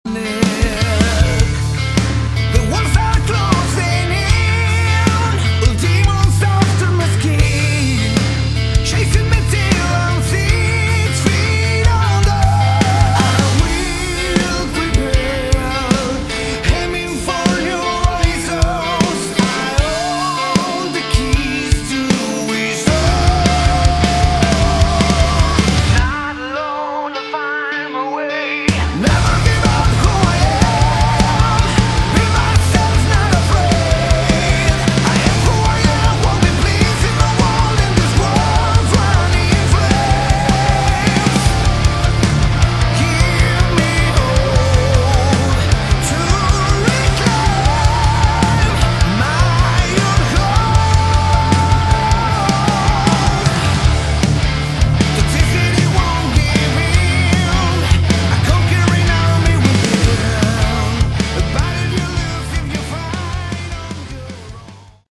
Category: Melodic Metal
vocals
guitar
keyboards, backing vocals
bass
drums